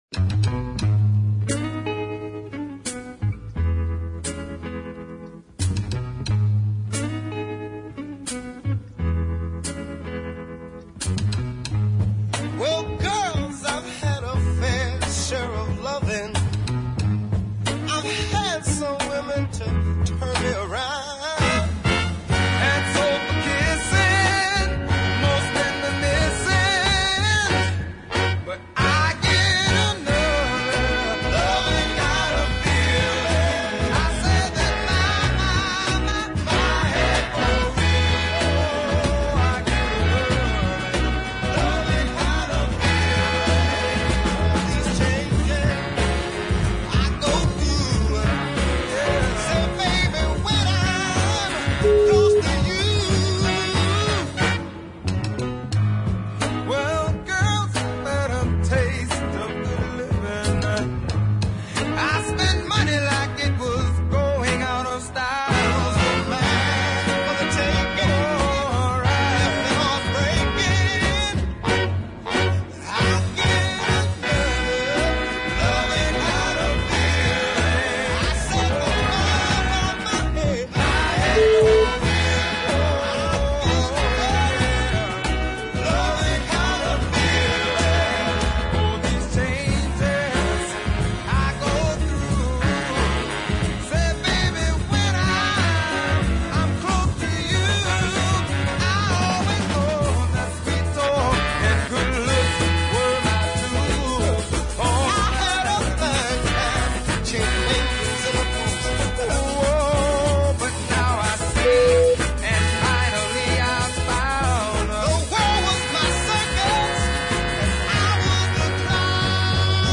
is a big city ballad full of righteous feeling